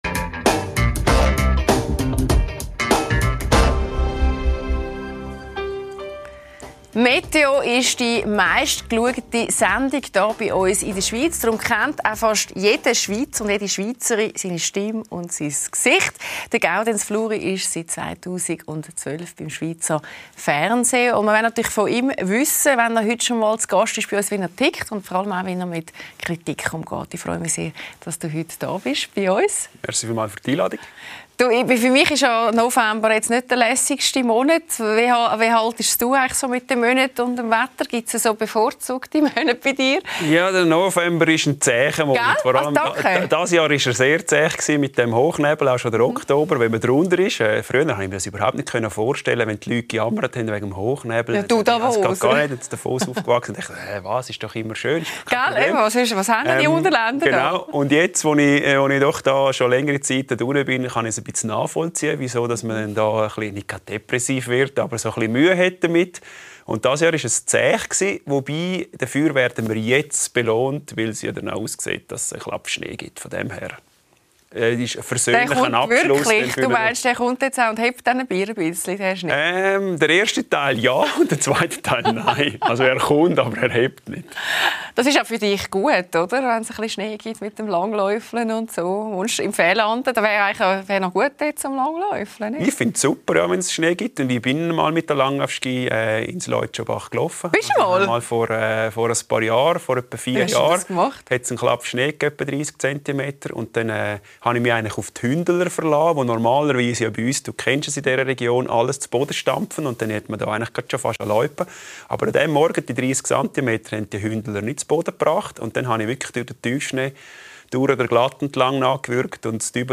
LÄSSER ⎥ Die Talkshow Podcast